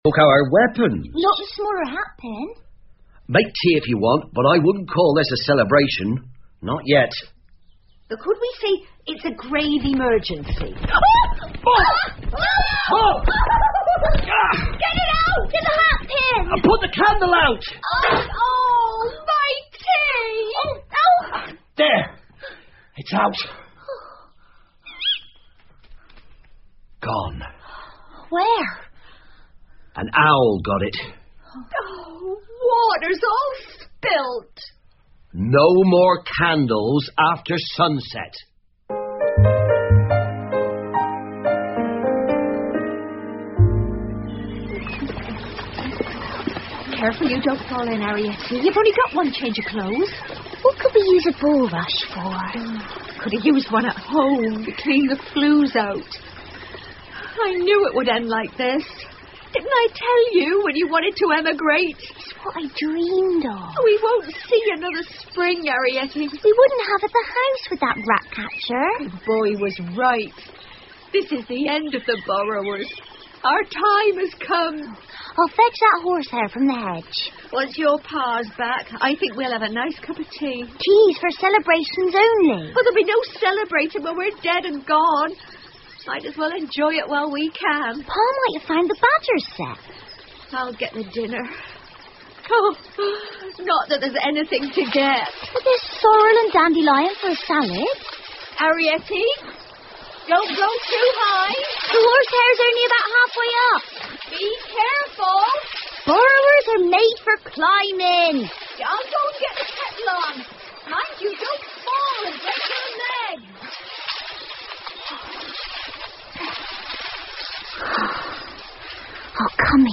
借东西的小人 The Borrowers 儿童广播剧 13 听力文件下载—在线英语听力室